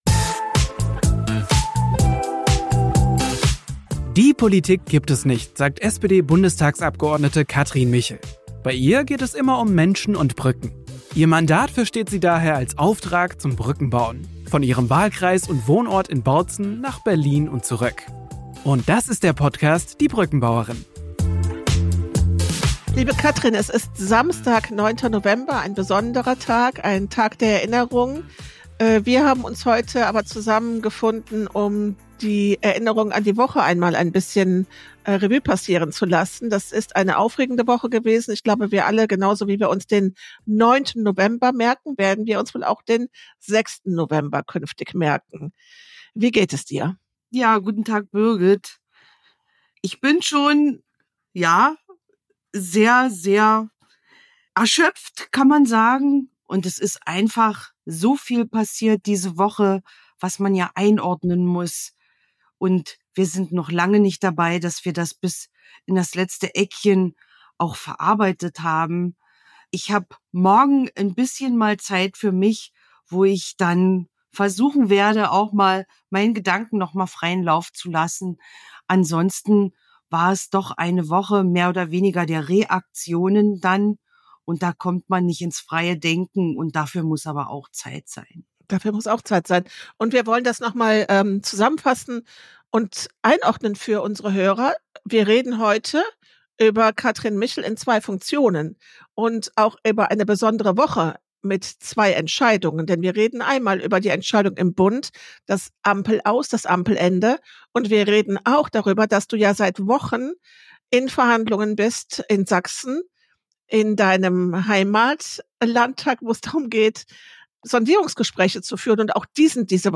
Wie die einzelnen Momente aus der Innensicht aussahen, was sie dachte, inwiefern sie als Haushaltsexpertin informiert war und wie sie auf die verschiedenen Situationen persönlich reagierte, davon berichtet die Bundestagsabgeordnete wie immer offen und nahbar in dieser Folge. Sie stellt sich allen Fragen der Zuhörer, auch unangenehmen.